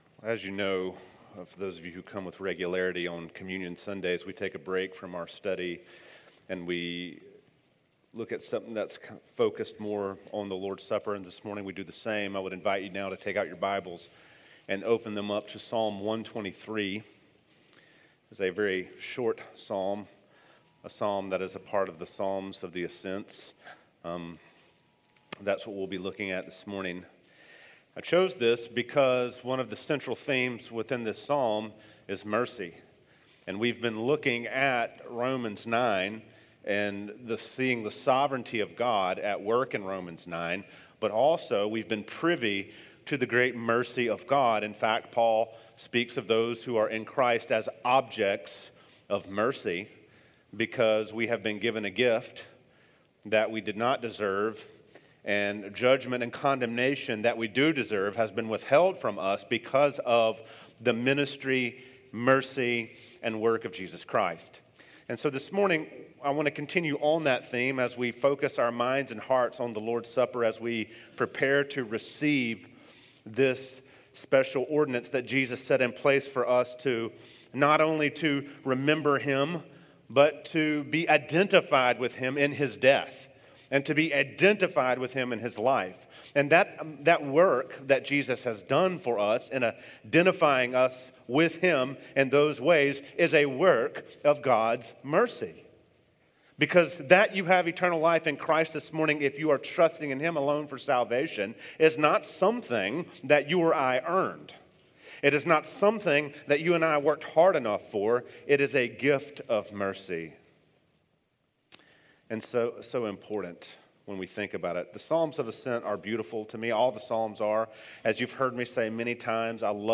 teaches from the series: Topical, in the book of Psalms, verses 123:1 - 123:4